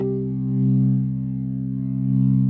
B3LESLIE C 3.wav